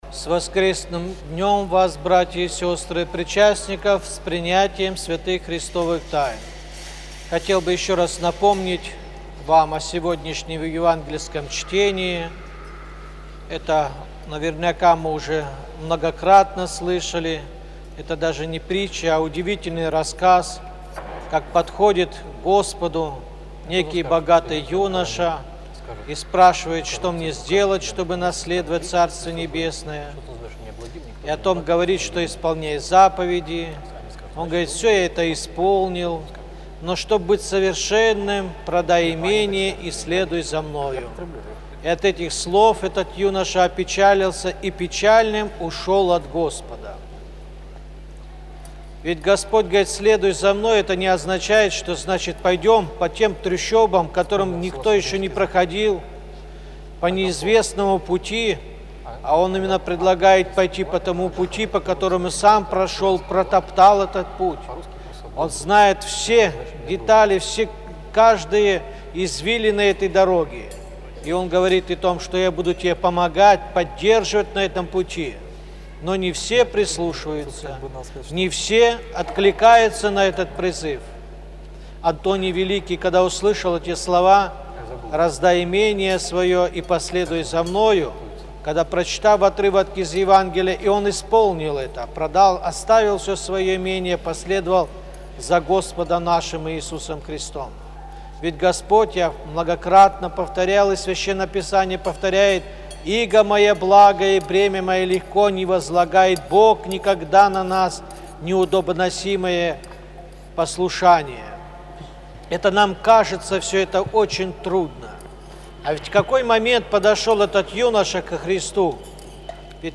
Проповедь на литургии читает епископ Балтийский Серафим